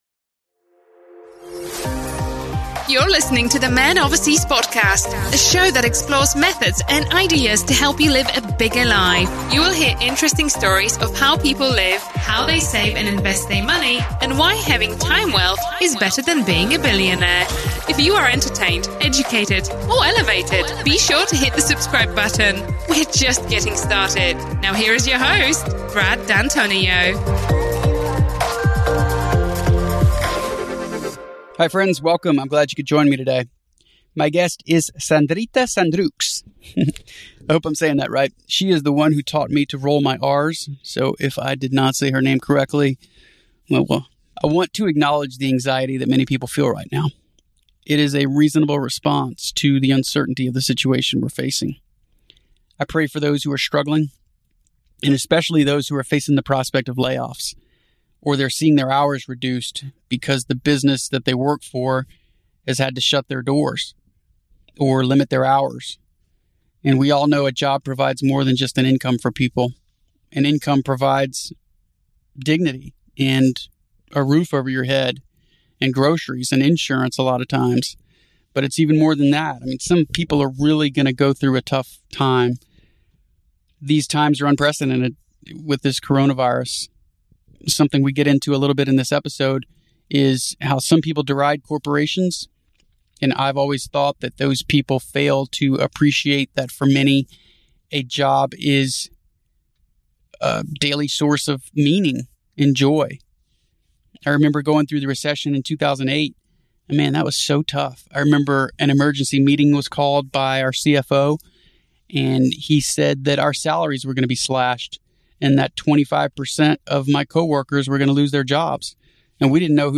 The podcast starts with a monologue (0:00 – 12:36). I talk about Coronavirus, its economic impact & give recommendations for how to think about investing during uncertain times.
Note: This podcast was recorded prior to the Coronavirus outbreak in the US.